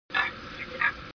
Turkey Vulture(no vocalizations)
Turkey Vulture.mp3